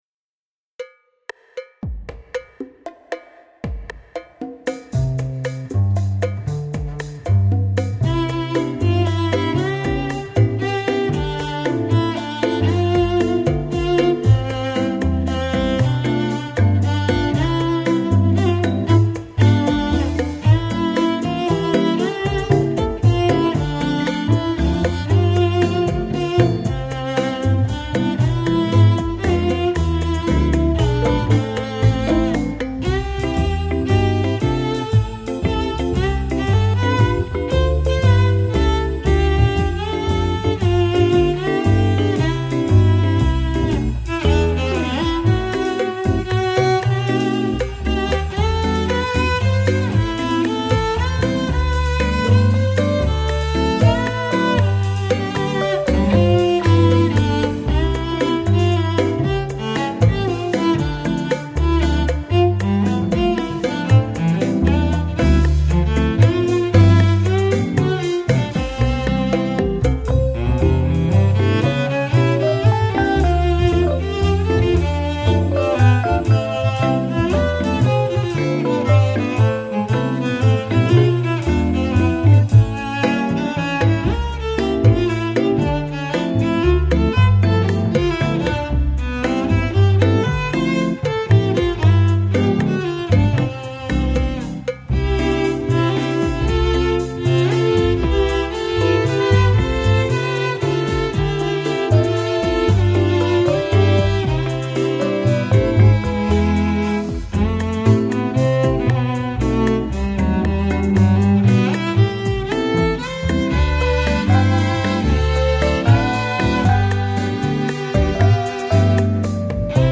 主要演奏器乐：大提琴